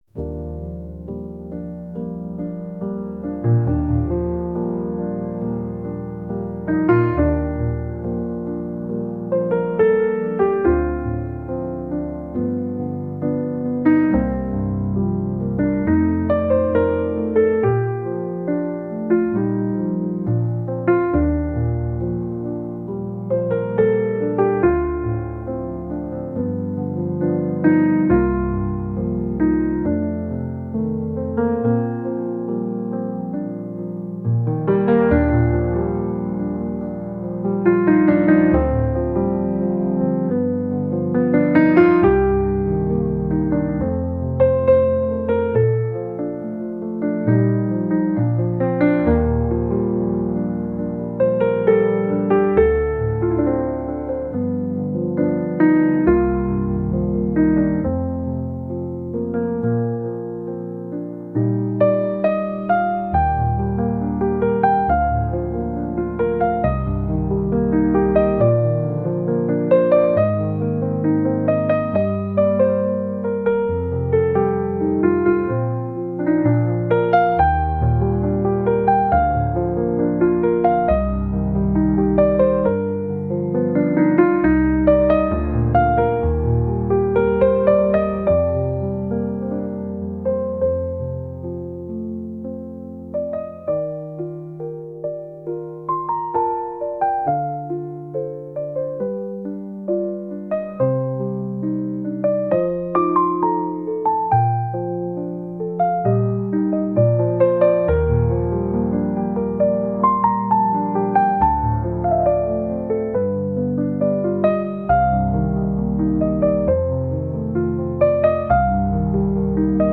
ジャンルJAZZ
楽曲イメージChill, Lo-Fi, ゆったり, カフェ, 日常, , 爽やか